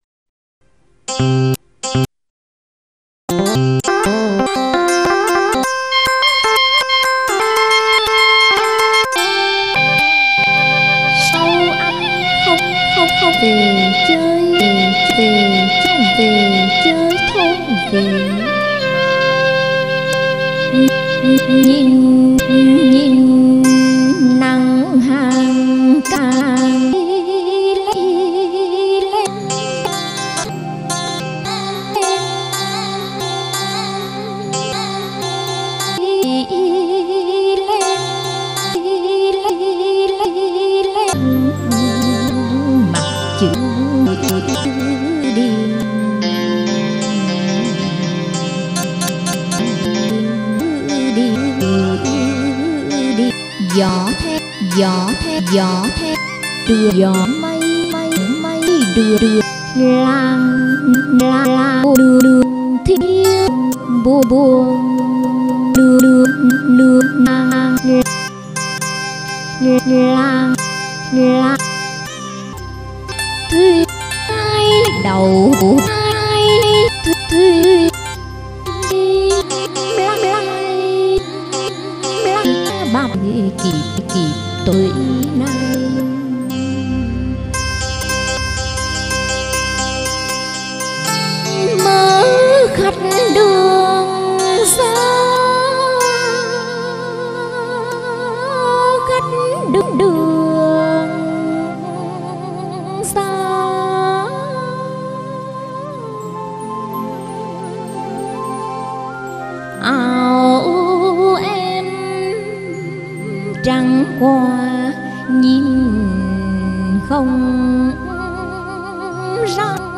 Ngâm